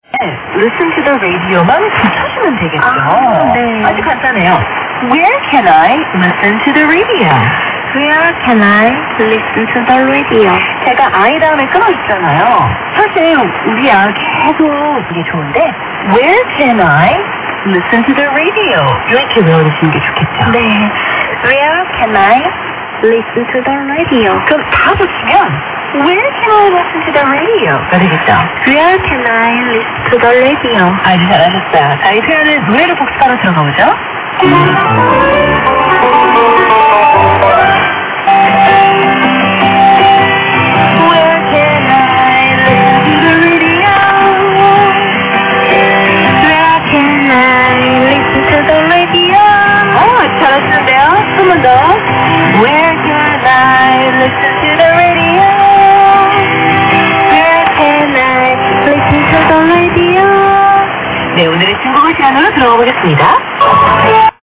Voice of Freedom on 6135kHz at 1303 UTC on May 13, 2014